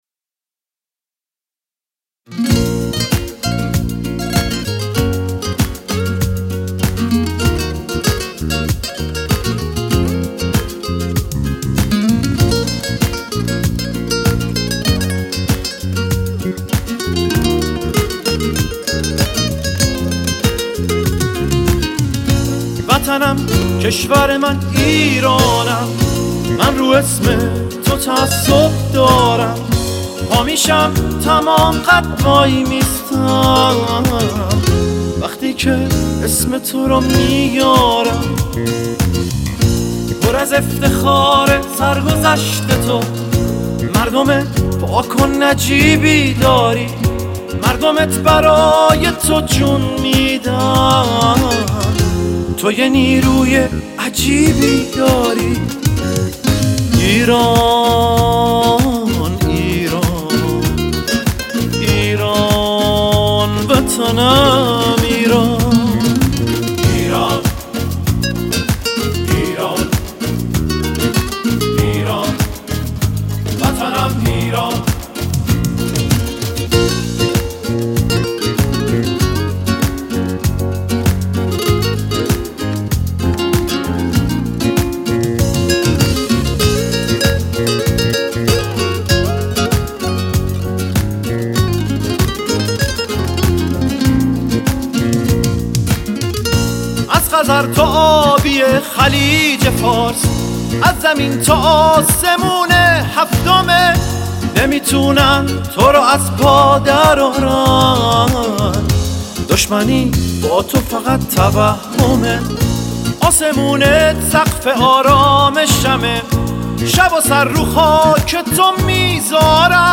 موسیقی پاپ، راک و جز